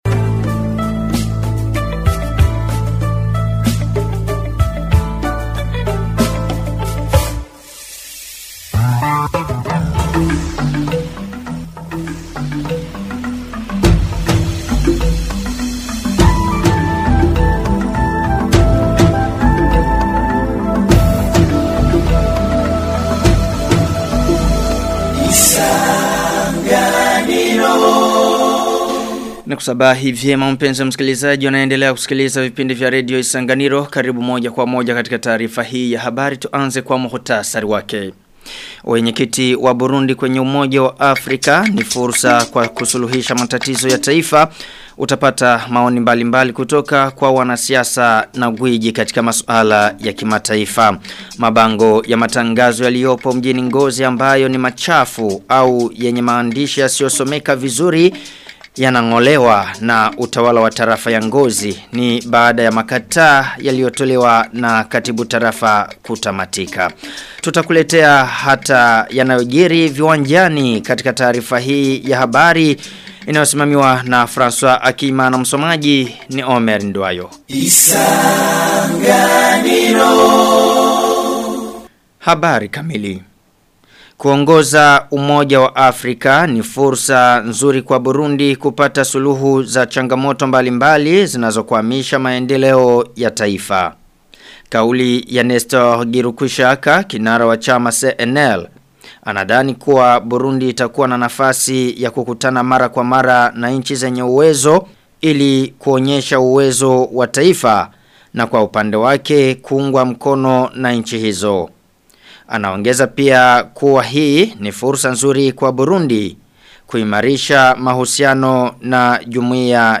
Taarifa ya habari ya tarehe 9 Februari 2026